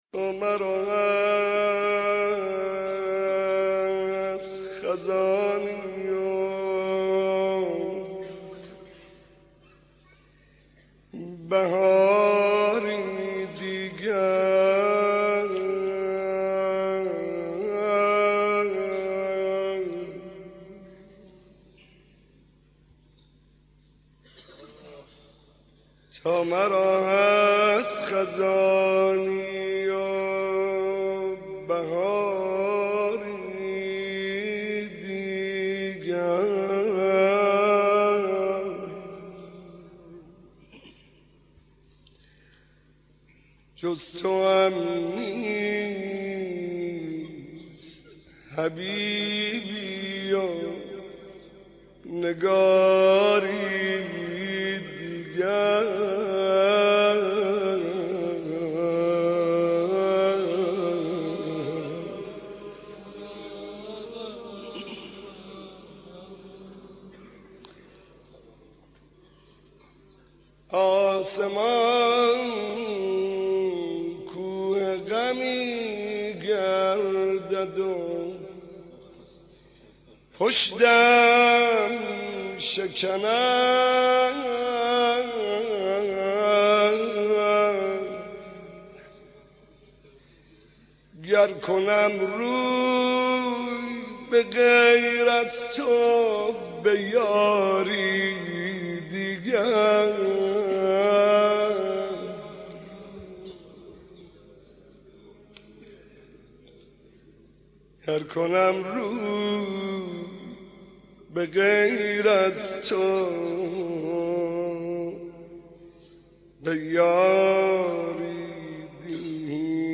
مداح
مناسبت : شب هشتم محرم